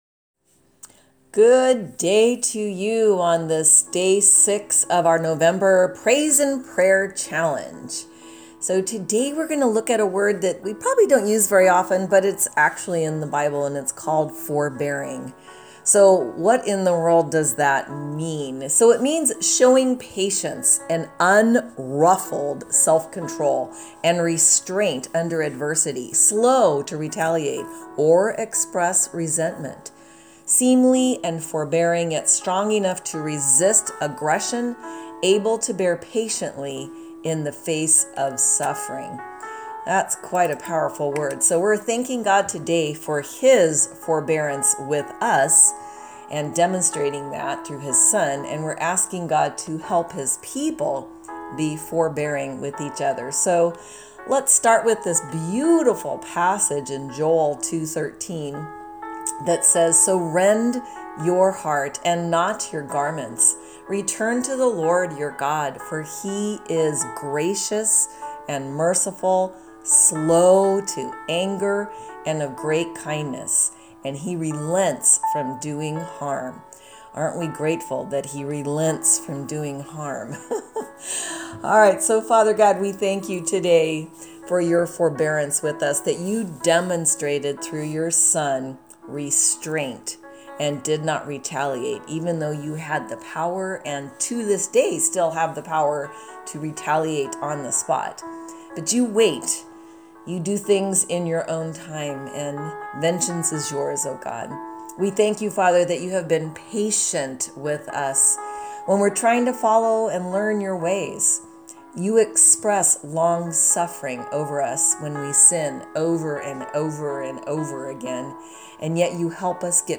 Today's Prayer Podcast